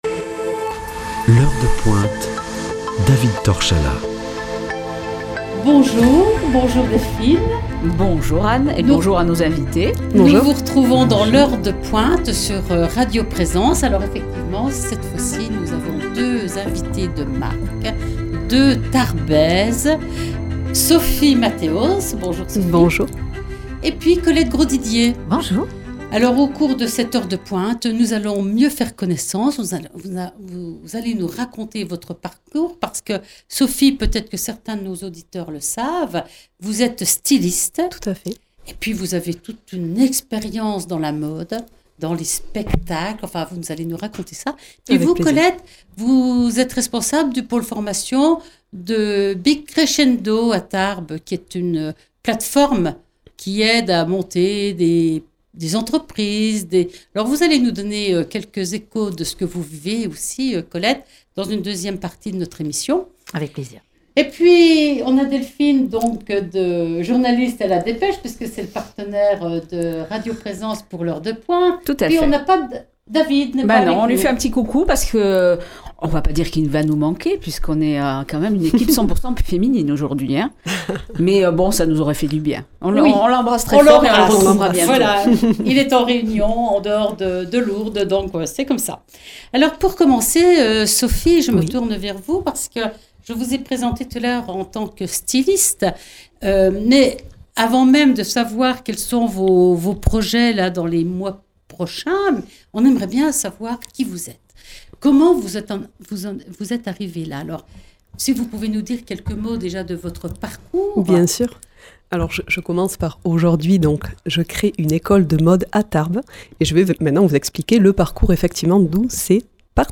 Rencontre avec deux Tarbaises d’exception !